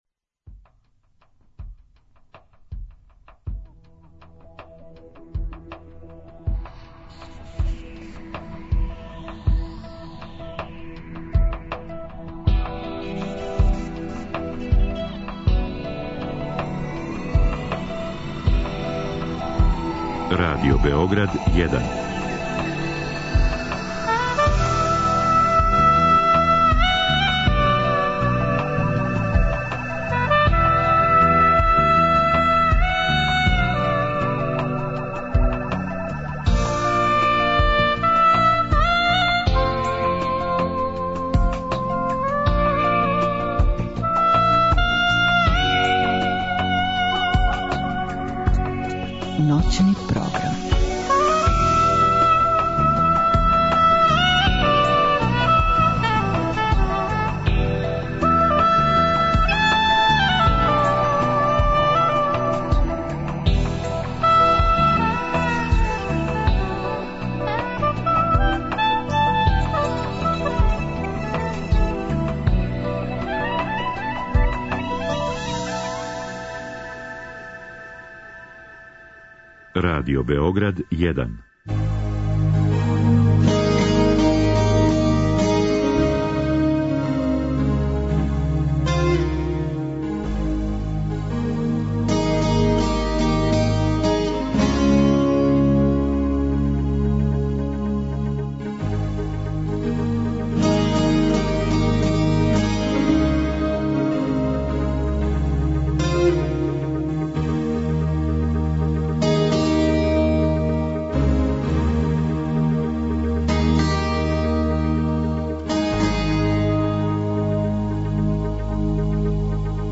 Разговор и добра музика требало би да кроз ову емисију и сами постану грађа за снове.
У другом делу емисије, од 02,05 до 04,00 часова, слушаћемо тонски запис сећања и размишљања Милоша Црњанског. Писац овде говори о свом животу, својим делима, о књижевним узорима и пријатељима, о вези између прошлости и садашњости.